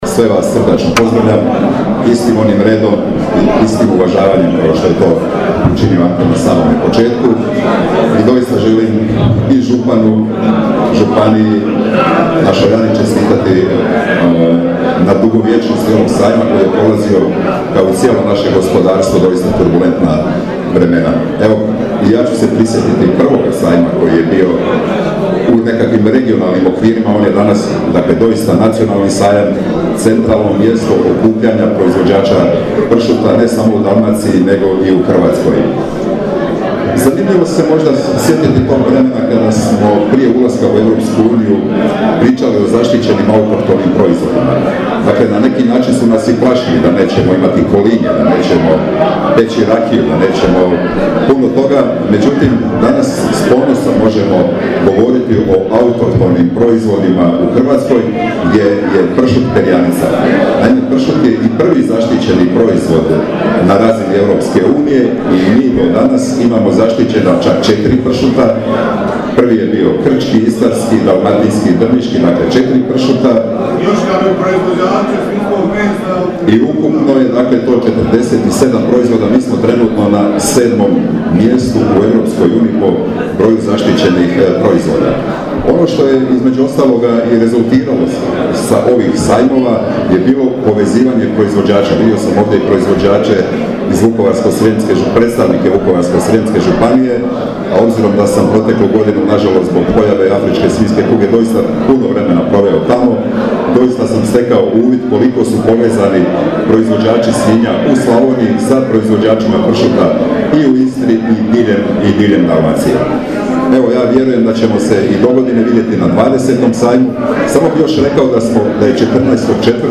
U ime ministrice poljoprivrede ovom događaju nazočio je državni tajnik Mladen Pavić koji se prisjetio prvog sajma i početka zaštite autohtonih proizvoda.
pavic-mladen-govor.mp3